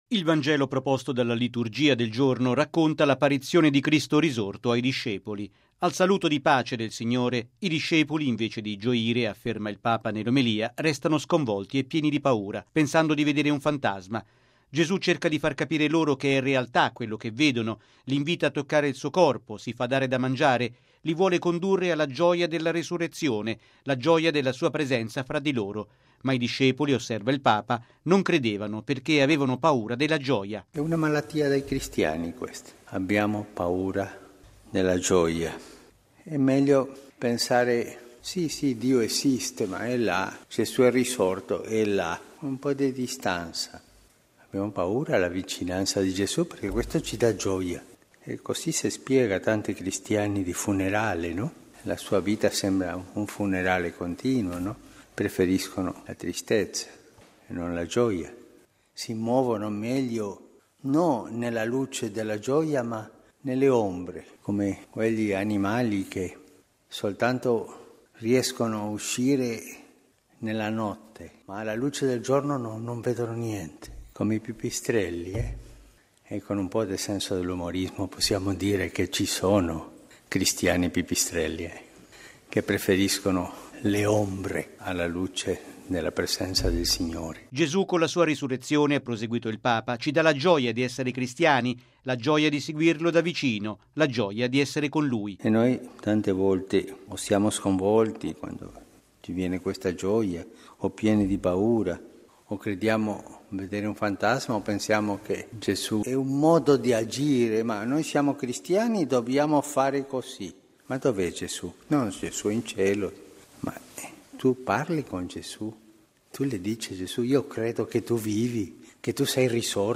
◊   Ci sono cristiani che hanno paura della gioia della Risurrezione che Gesù ci vuole donare e la loro vita sembra un funerale, ma il Signore risorto è sempre con noi: è quanto ha affermato il Papa durante la Messa presieduta a Santa Marta.